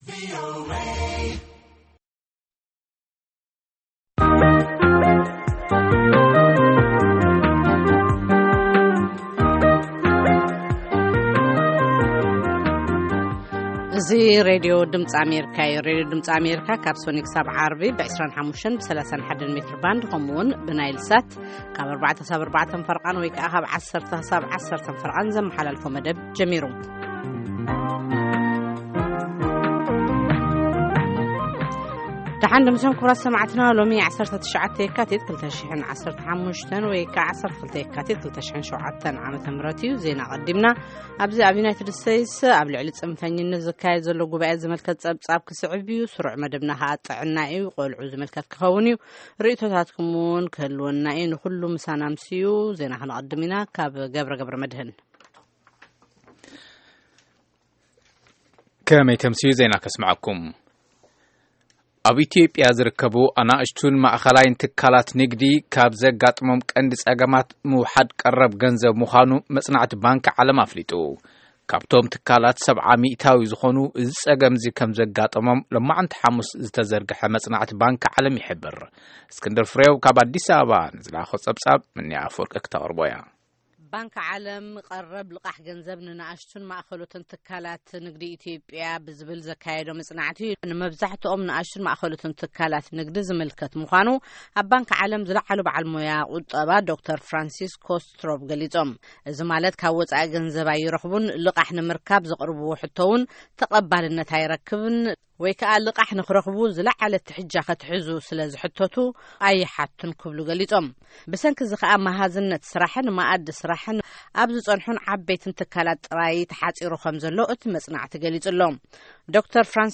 Tigrigna News